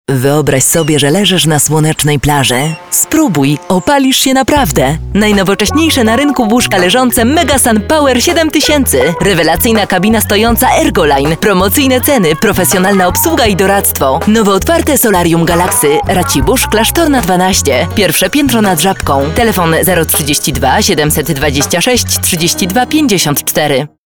polnische Profi- Sprecherin für TV/Rundfunk/Industrie.
Sprechprobe: Sonstiges (Muttersprache):